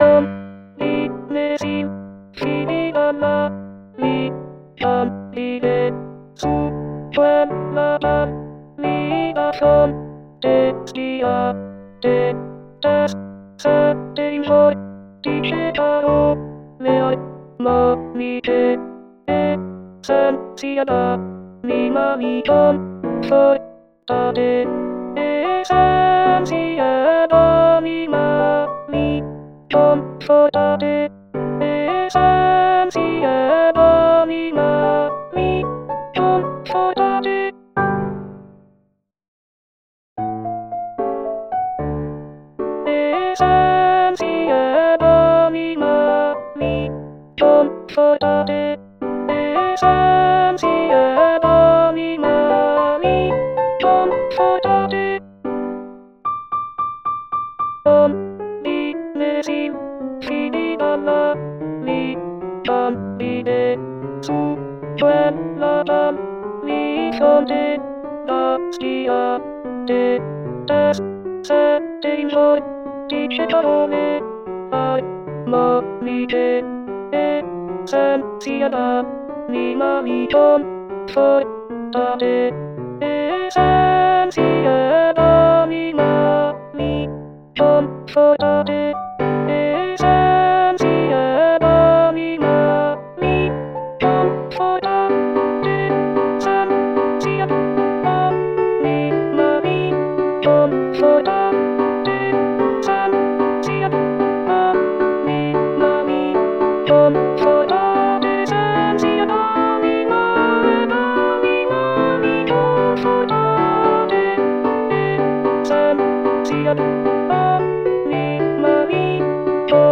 ALTOS
alto-1-coro-e-ballabile-reduction.mp3